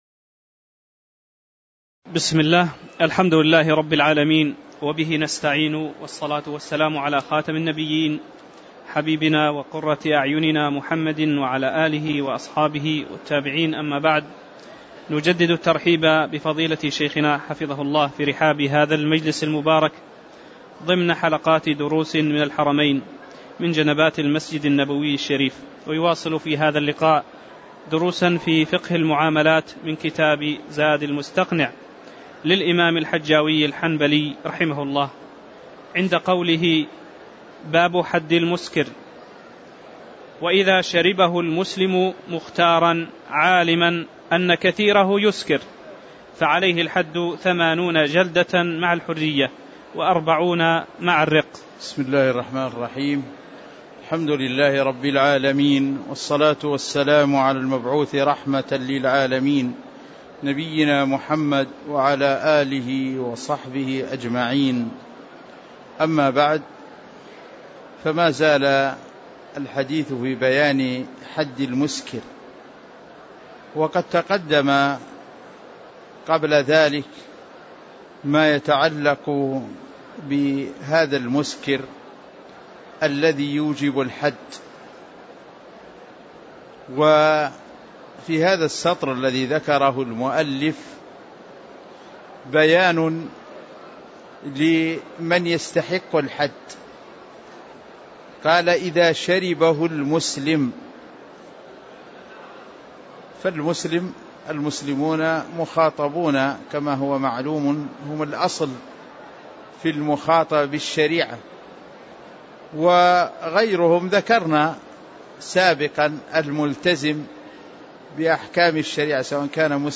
تاريخ النشر ٢٣ جمادى الأولى ١٤٣٨ هـ المكان: المسجد النبوي الشيخ